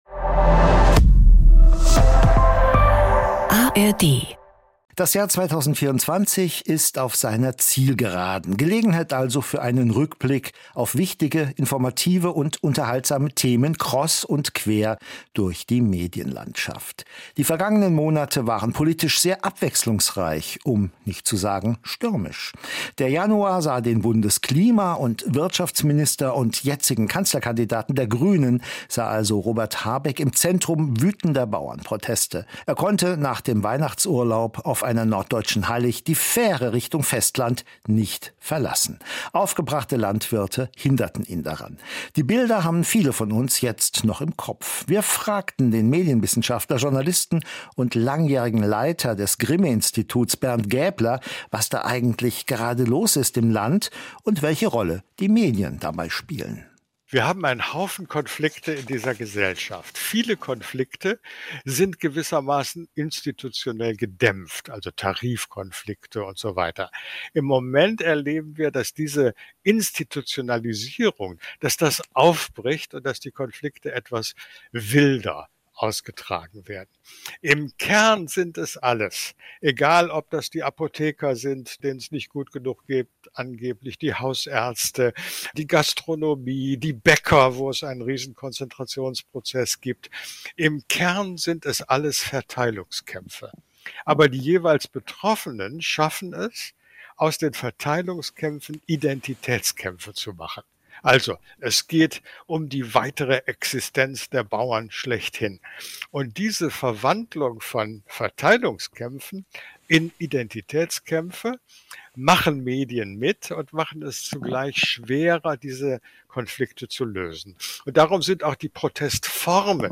Die Moderatorinnen und Moderatoren informieren, diskutieren und räsonieren jede Woche über neue Trends und kontroverse Themen aus der großen weiten Welt der Medien. Immer zu zweit, immer voller Meinungsfreude und immer auch mit fundierten und gründlich recherchierten Fakten. Gespräche mit Experten und Expertinnen, Umfragen unter Nutzerinnen und Nutzern und Statements aus der Medienbranche können Probleme benennen und Argumente untermauern.